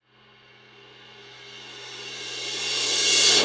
Cymbol Shard 18.wav